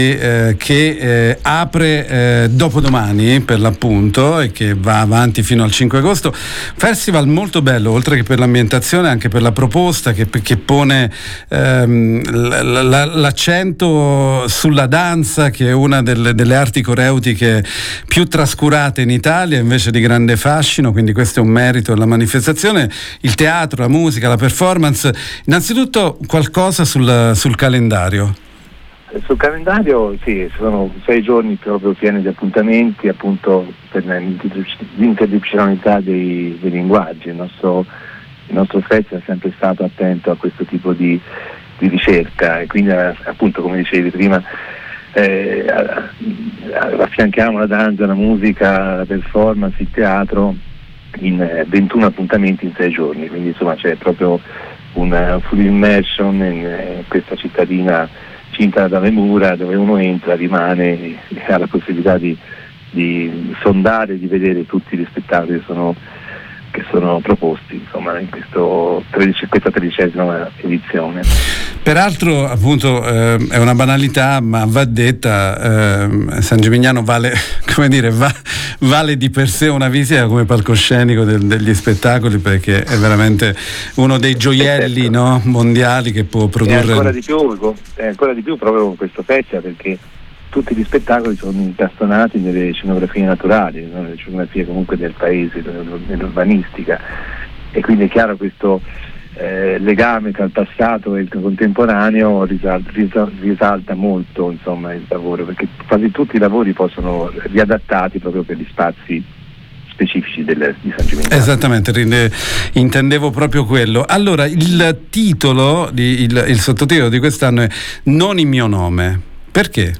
Il Festival è reso in dubbio dai tagli del Governo. Intervista